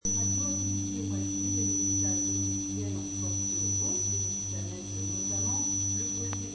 Problème fréquence parasite pompe à chaleur
Et mon problème est que lorsque le groupe extérieur se met en route il me balance dans l'installation une fréquence parasite qui passe dans mon système de son (guitare, effets, amplification, carte son etc.) et il m'est impossible de travailler (enregistrer) sans ce sifflement strident permanent.
Il s'agit bien de ce type de fréquence.
bruitpac2.mp3